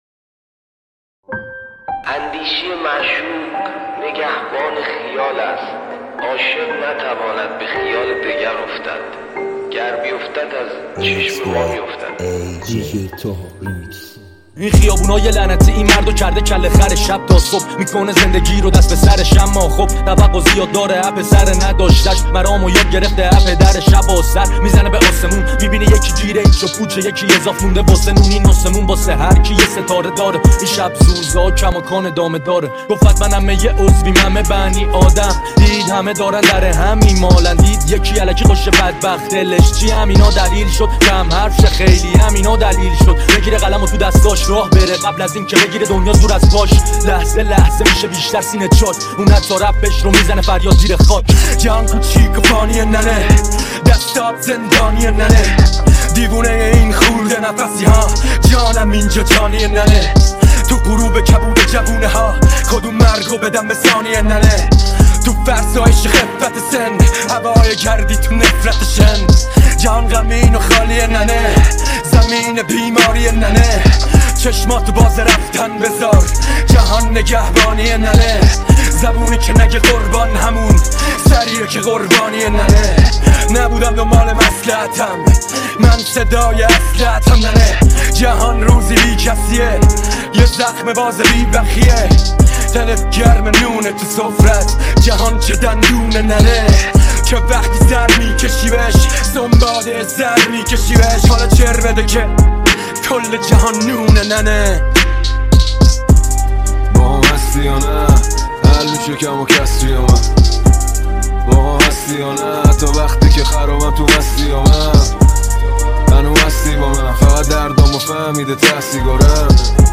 ریمیکس رپ فارسی